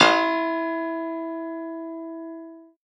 53v-pno04-E2.aif